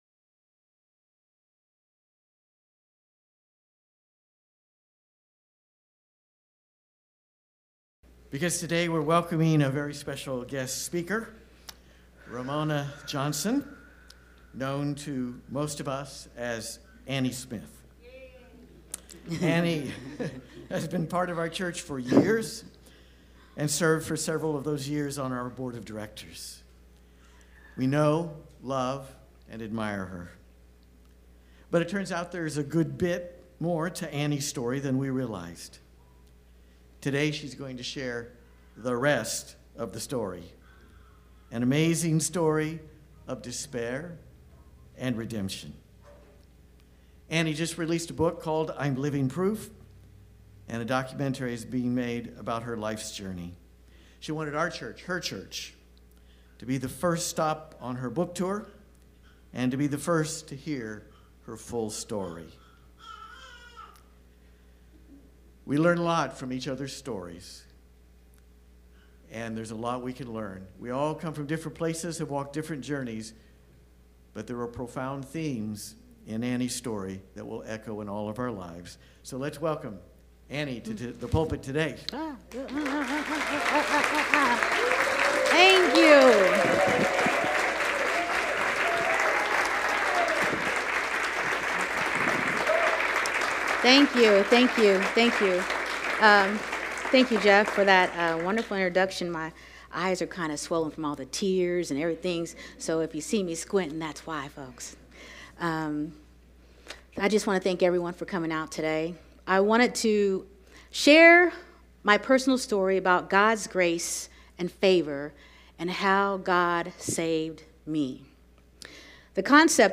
LIVE Stream Replay